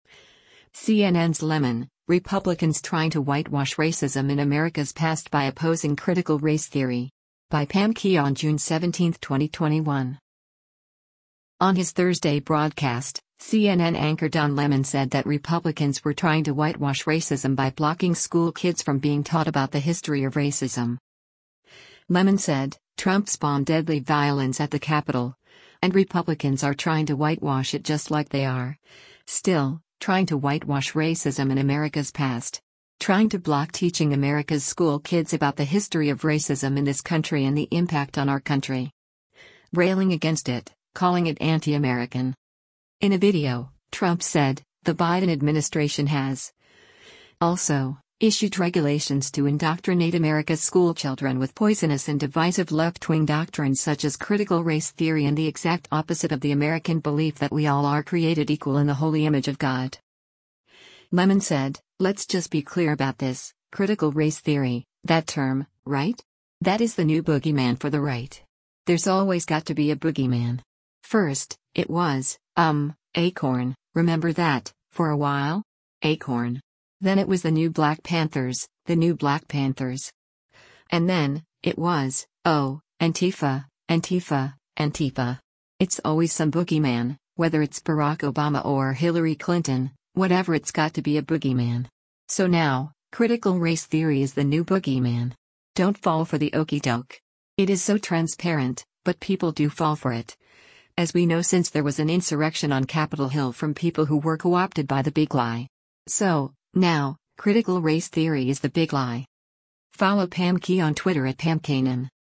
On his Thursday broadcast, CNN anchor Don Lemon said that Republicans were “trying to whitewash racism” by blocking school kids from being taught about the history of racism.